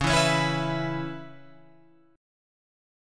C#m7 chord